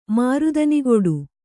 ♪ mārudanigoḍu